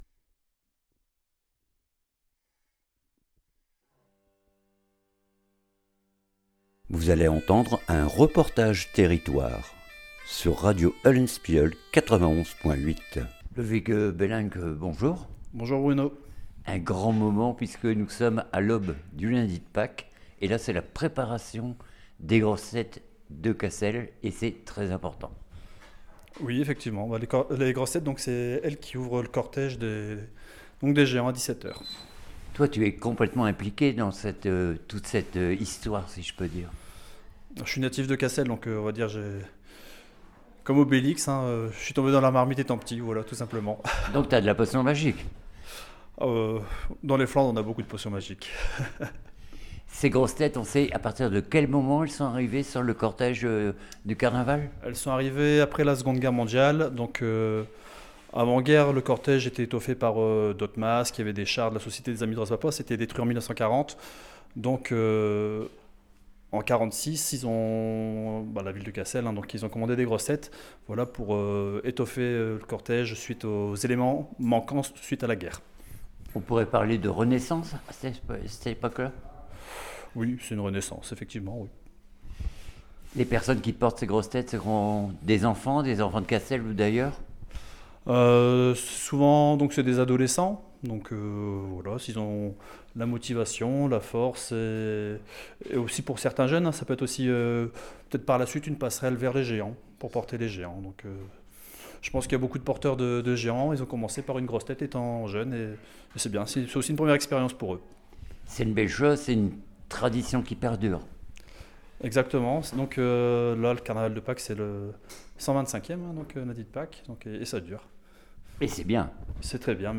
REPORTAGE TERRITOIRE LES GROSSES TETES DE CASSEL